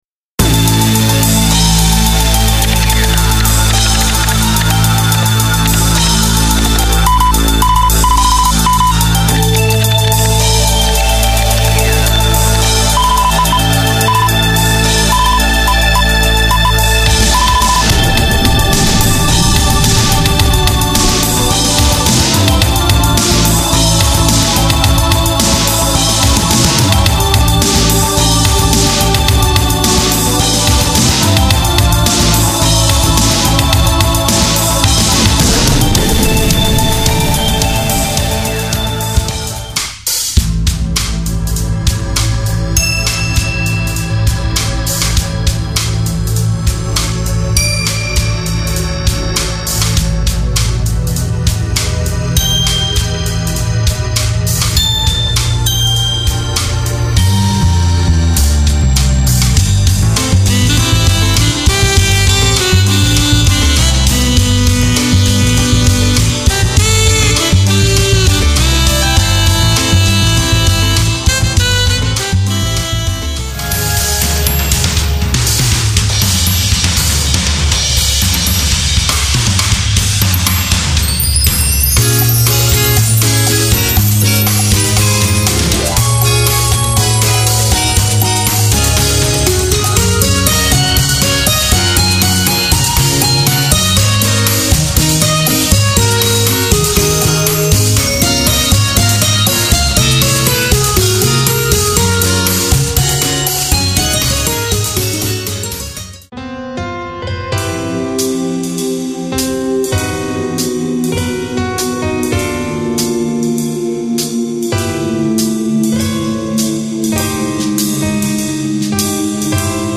ハードロック・ジャズ・フュージョン調など
なんでもアリアリのアレンジ集です。
ちょっと未来的でダークな雰囲気に仕上がってます。